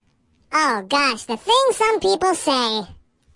描述：一个人声线的记录和处理，就像一个卡通花栗鼠说的一样。 录音是在Zoom H4n上进行的。使用MOTU Digital Performer中的Spectral Effects进行操纵